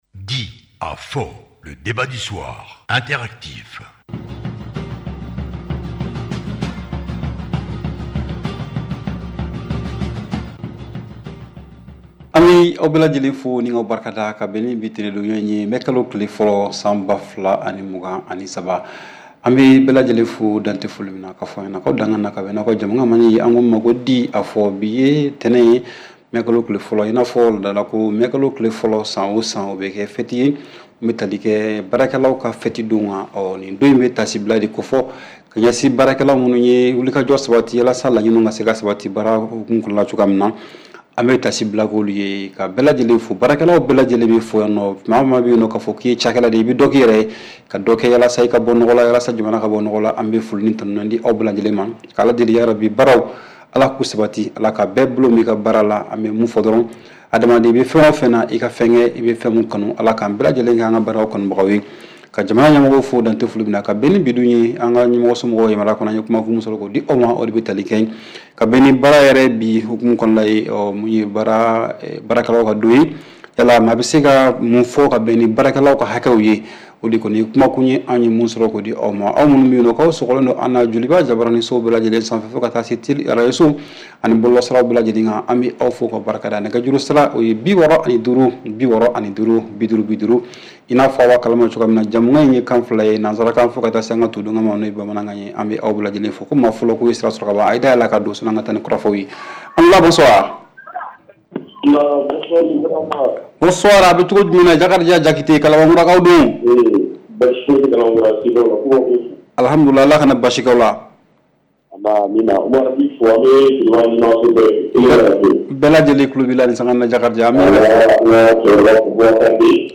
REPLAY 01/05 – « DIS ! » Le Débat Interactif du Soir